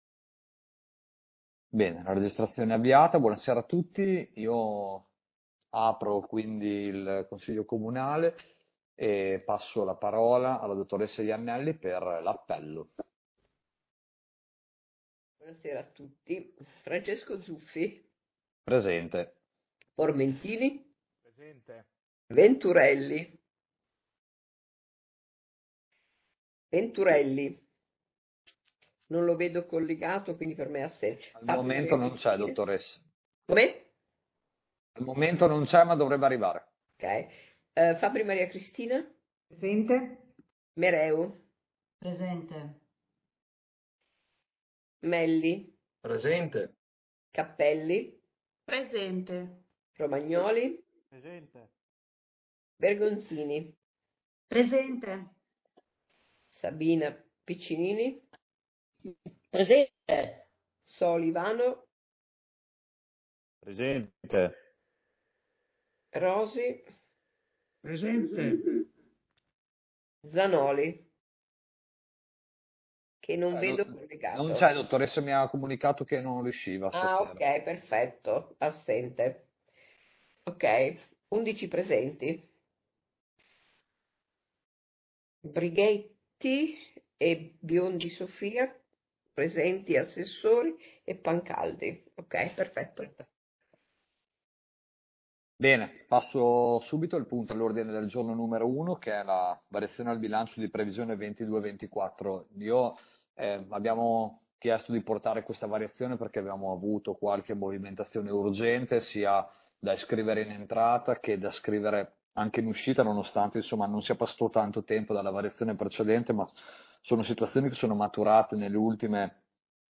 Consiglio Comunale del 3 marzo 2022